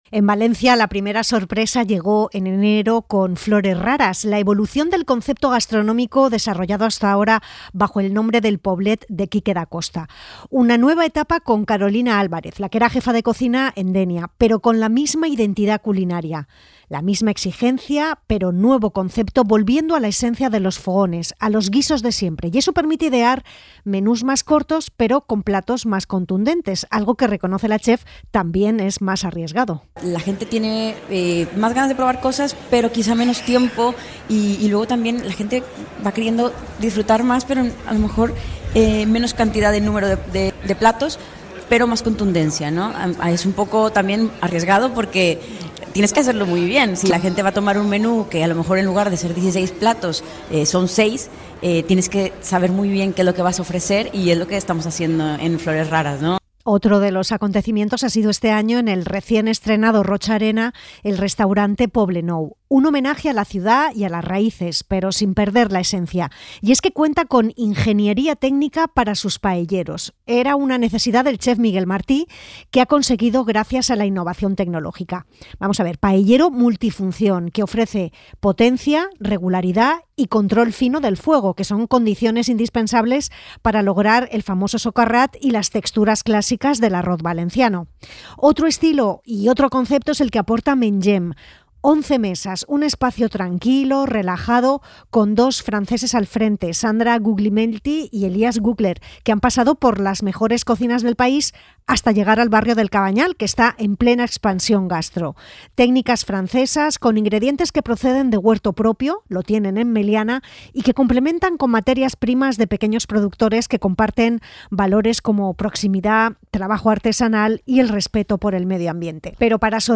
Reportaje | Nuevos restaurantes de València – Podium Podcast